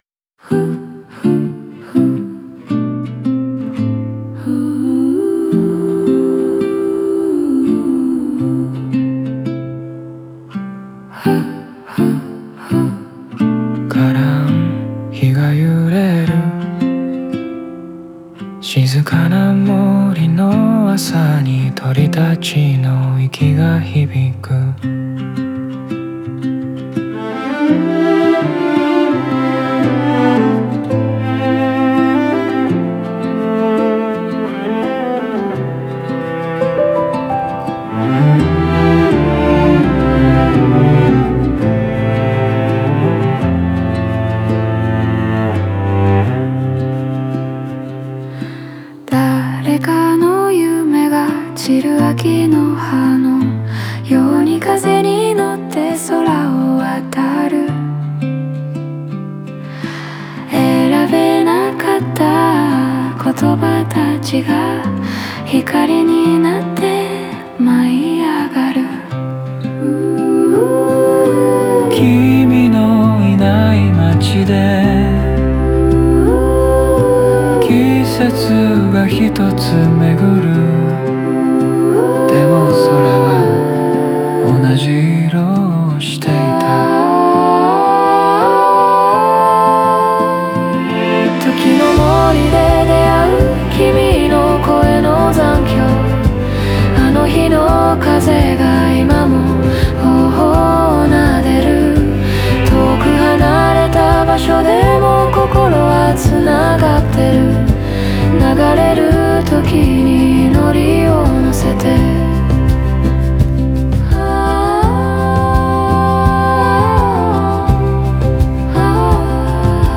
オリジナル曲♪
ストリングスとバックコーラスが包み込むように響き、聴く人に安心感と優しい郷愁を与える。